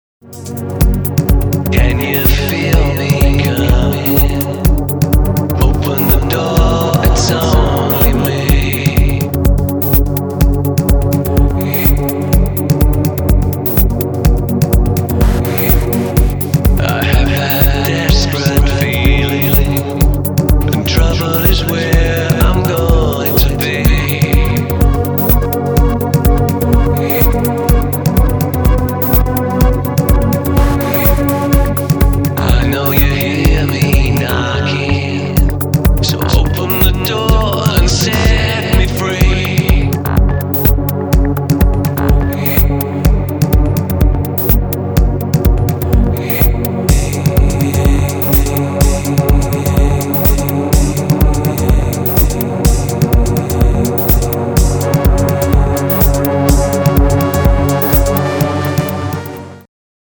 Club Mix